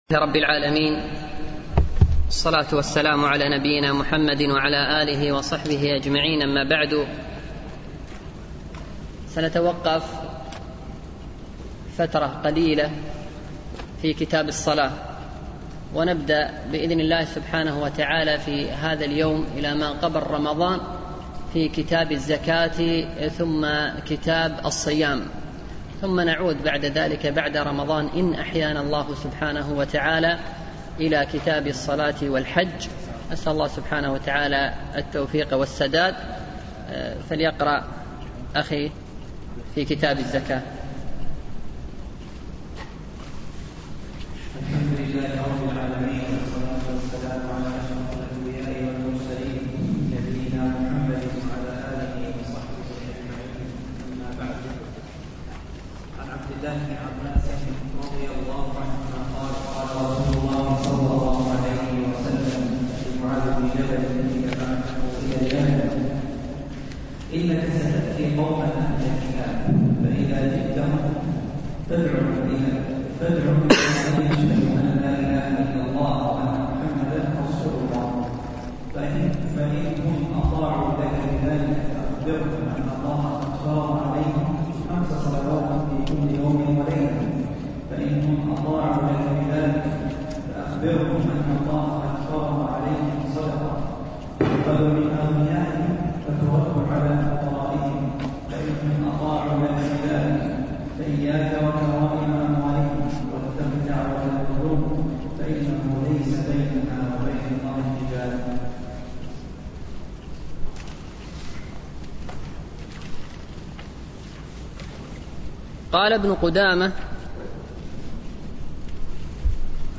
شرح عمدة الأحكام ـ الدرس السادس عشر